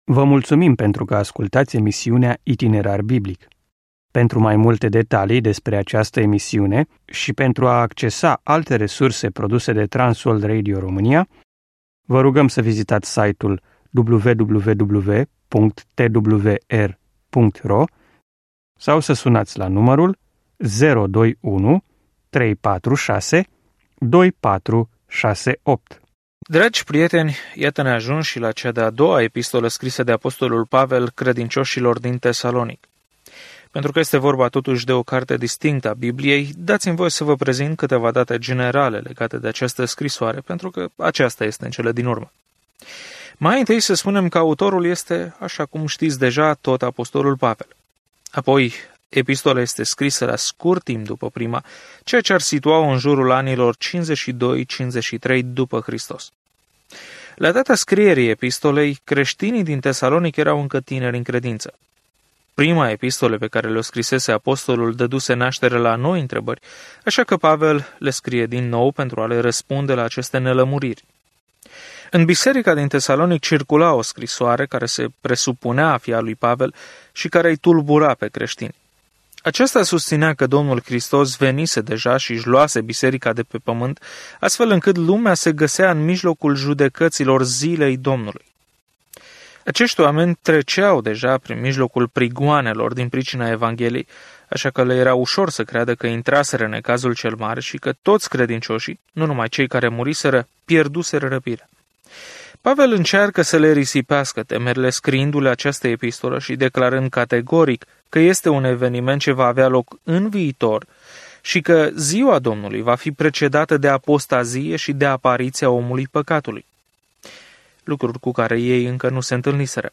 Este provocarea acestei a doua scrisori către Tesaloniceni care ne amintește că Isus se întoarce pentru noi. Călătoriți zilnic prin cei 2 Tesaloniceni în timp ce ascultați studiul audio și citiți versete selectate din Cuvântul lui Dumnezeu.